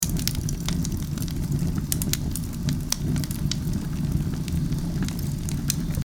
fireplace-06.mp3